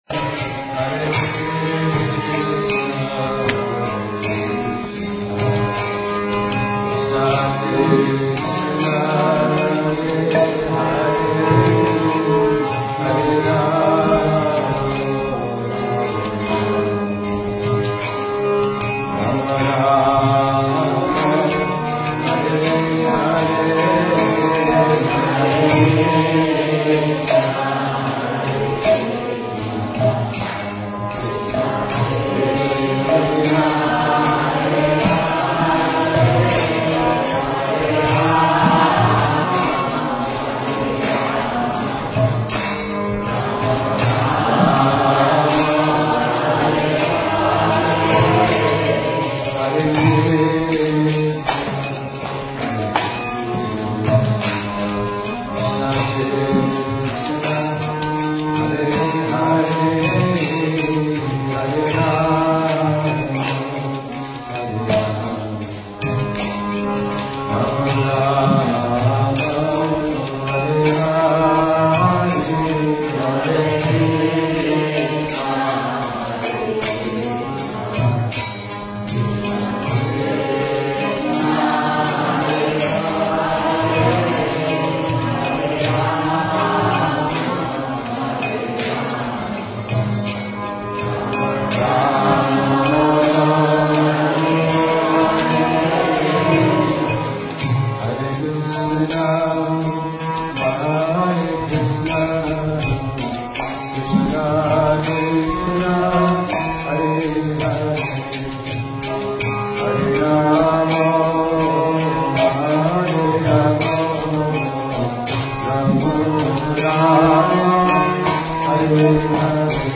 Kirtana
2008 Namamrita Experience